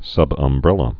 (sŭbŭm-brĕlə)